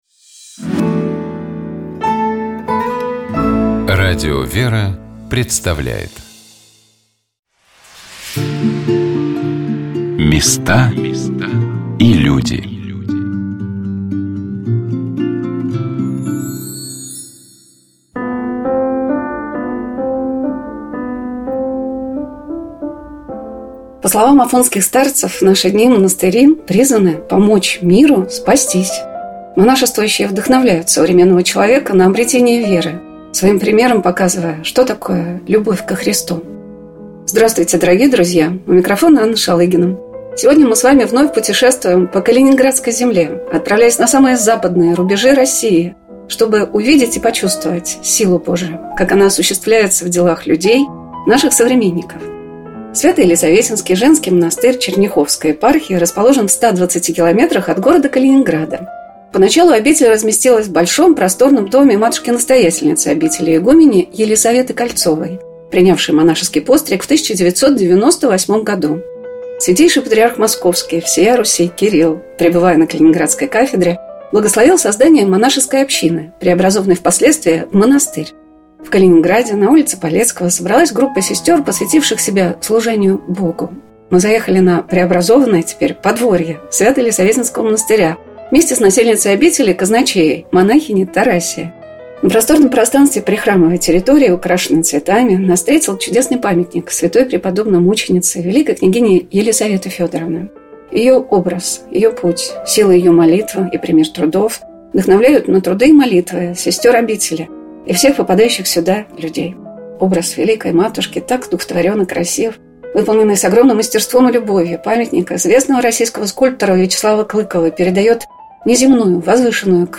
«Вечер воскресенья» - это разговор с людьми об их встрече с Богом и приходе к вере. Это разговор о том, как христианин существует в современном мире и обществе, как профессиональная деятельность может гармонично сочетаться с верой.